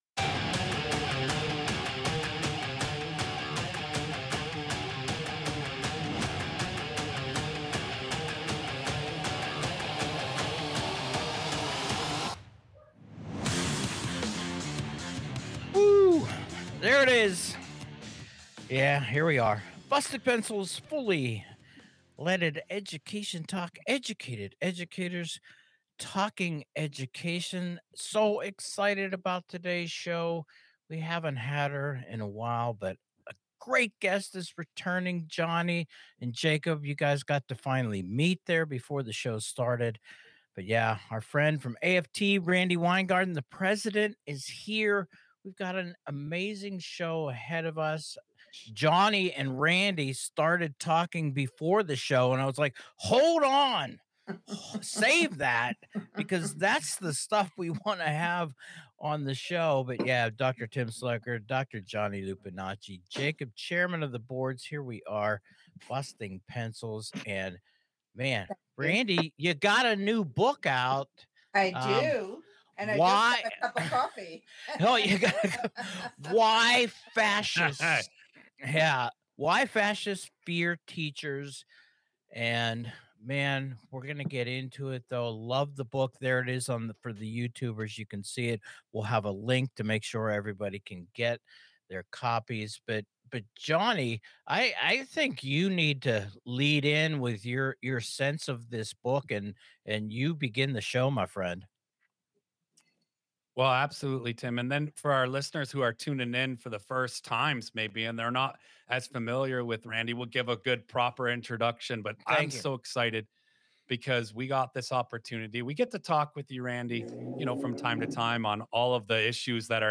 Randi Weingarten of the American Federation of Teachers joins us to discuss her new book, Why Fascists Fear Teachers.